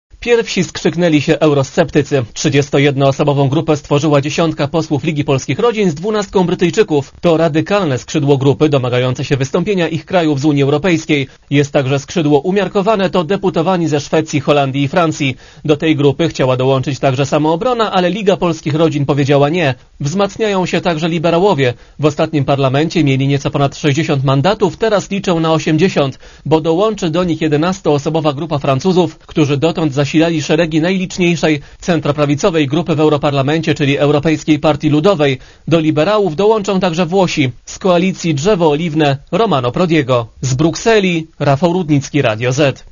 relacji